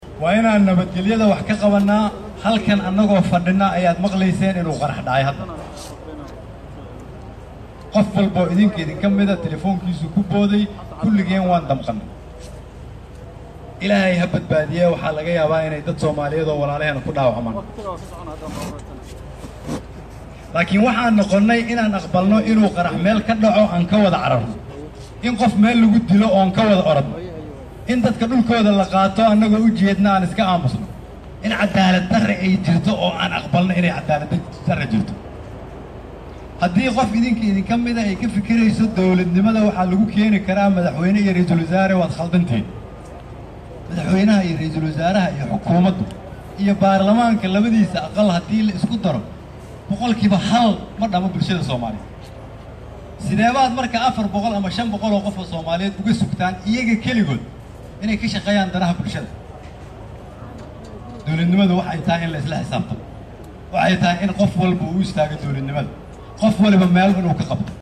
RW Kheyre ayaa si adag uga hadlay xasuuqii argagixisadu ku bilowdey dad afur u fadhiya maqaaxi ku taal bartamaha Muqdisho halkaas oo tirade dhimashadu gaarayso ilaa 20 qof.